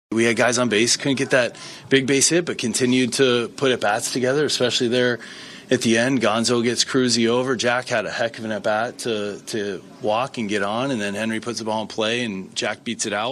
Manager Don Kelly says Jack Suwinski’s 10-pitch walk and Davis’s comebacker were key to the win.